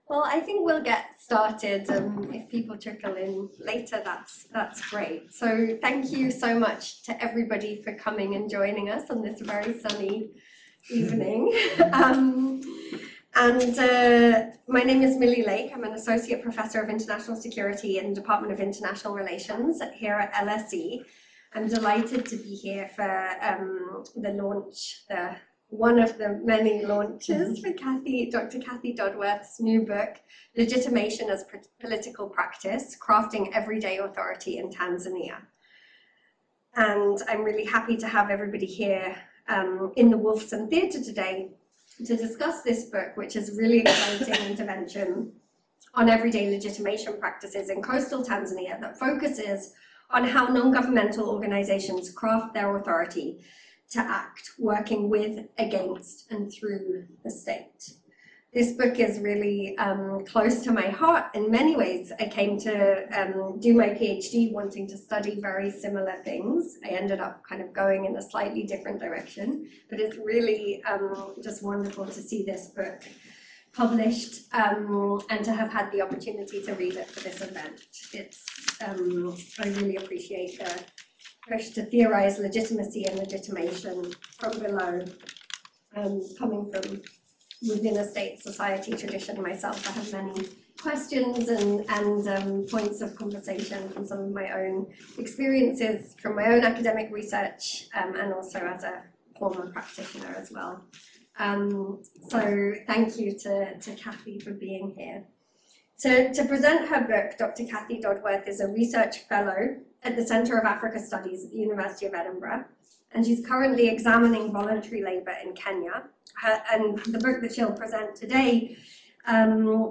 Listen to or download podcasts from our 2023 public events at the Department of International Relations at LSE